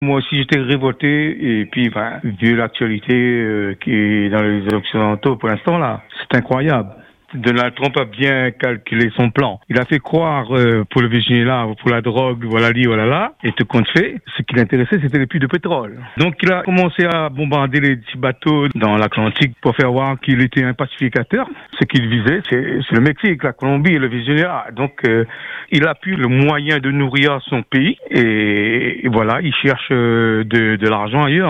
Une auditrice réunionnaise s’est exprimée avec colère face à ce qu’elle perçoit comme une fuite en avant interventionniste des États-Unis.